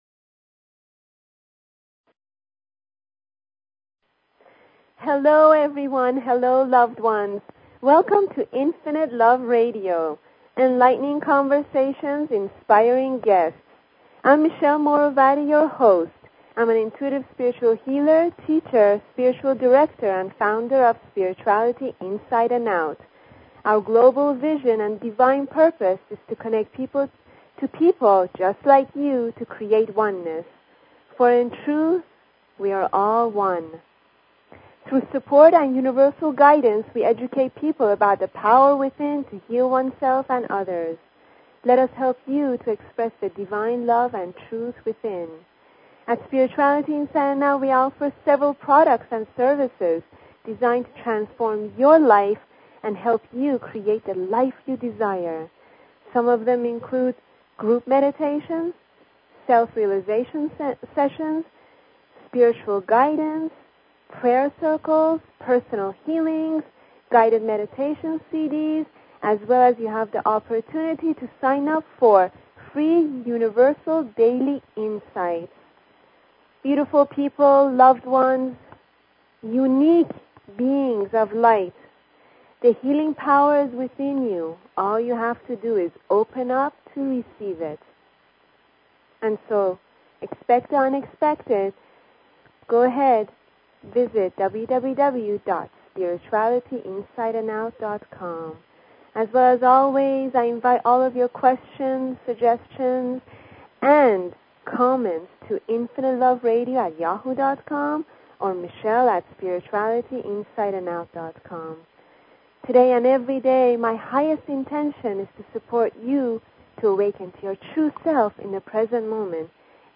Talk Show Episode, Audio Podcast, Infinite_Love_Radio and Courtesy of BBS Radio on , show guests , about , categorized as